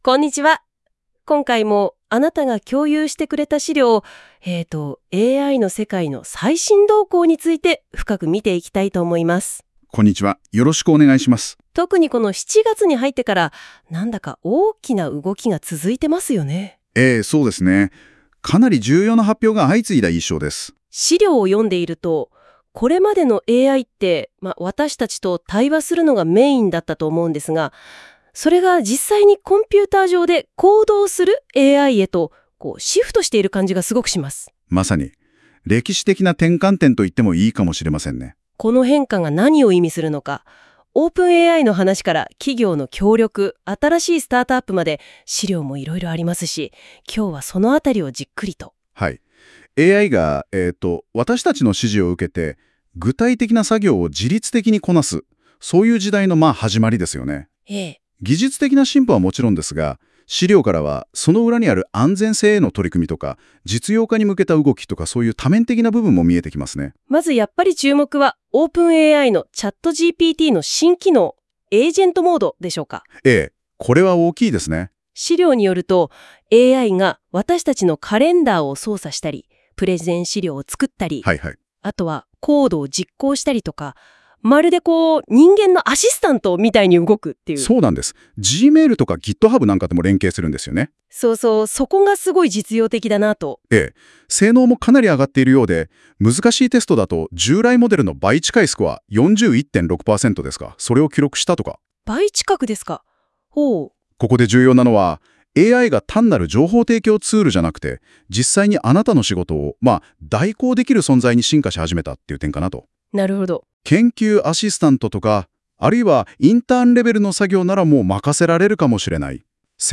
🎧 この記事の解説音声
忙しい方のために、この記事をポッドキャスト形式で解説しています。
※ この音声は Google NotebookLM を使用して記事内容から生成されています。